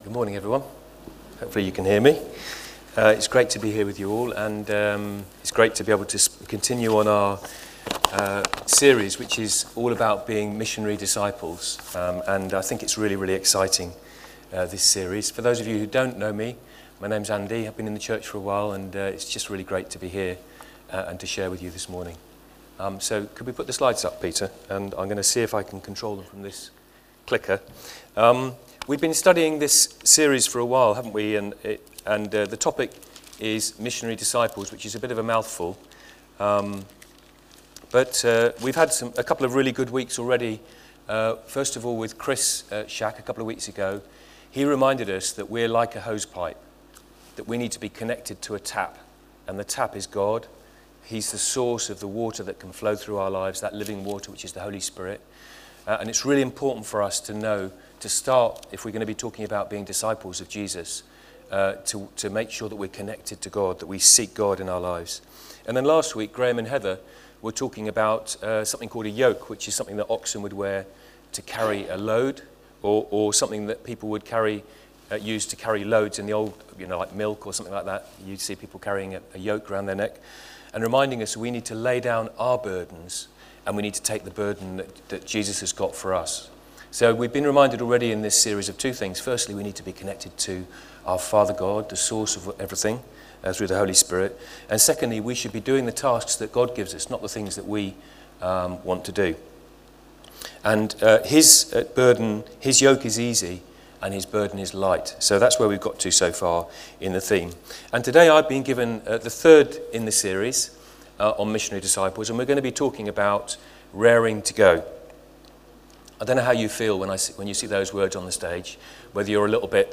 Talk
Short, child-friendly, talk about carrying the yoke of Jesus